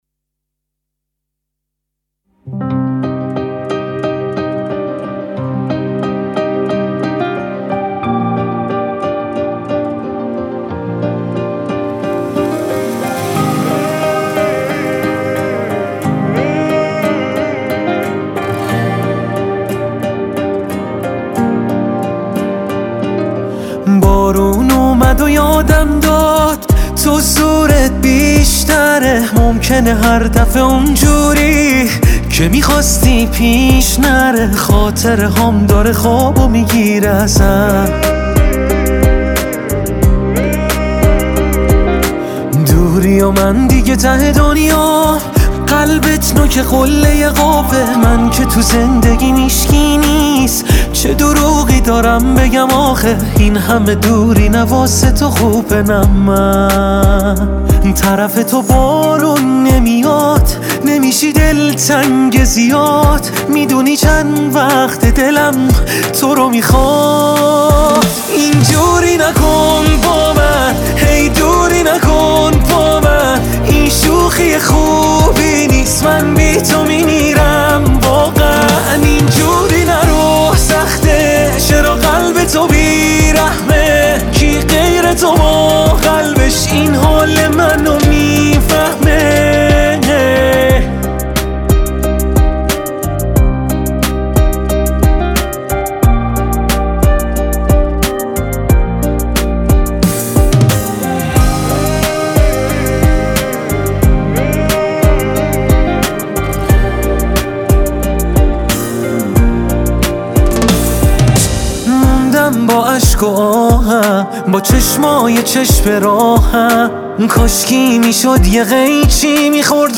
ترانه ماندگار و احساسی